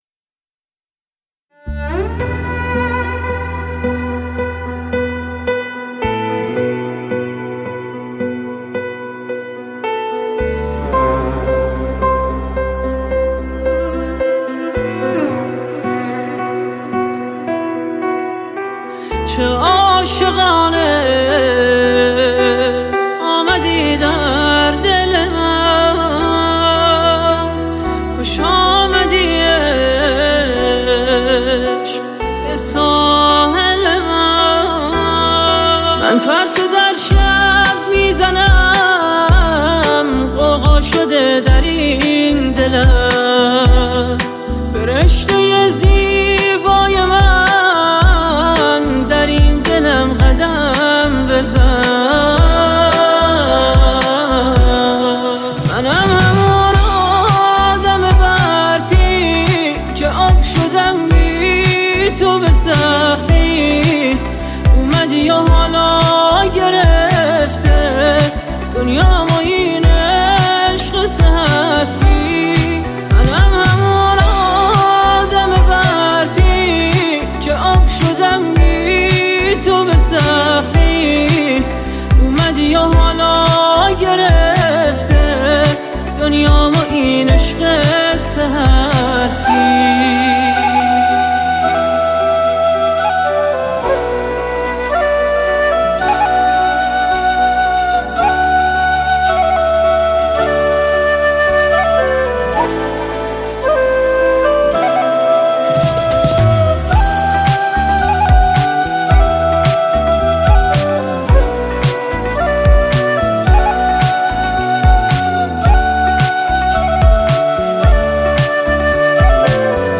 همخوانی دسته حمعی زن و مرد